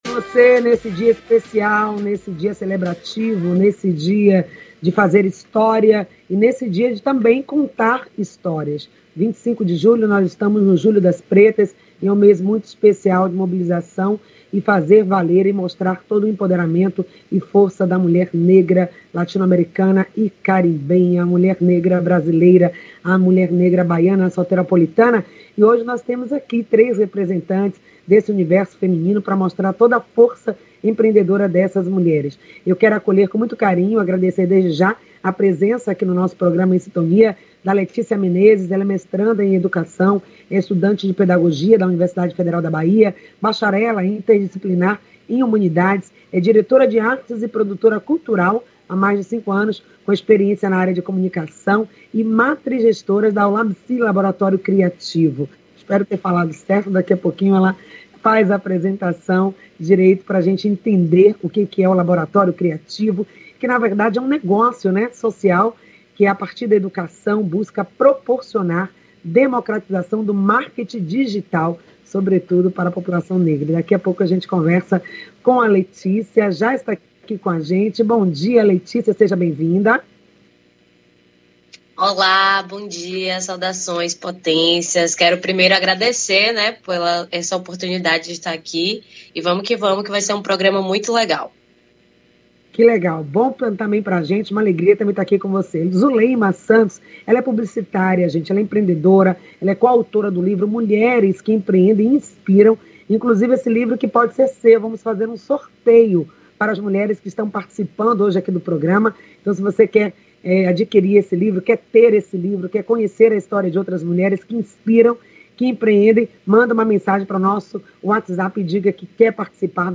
O programa Em Sintonia acontece de segunda à sexta, das 9 às 9:55h, pela Rádio Excelsior AM 840.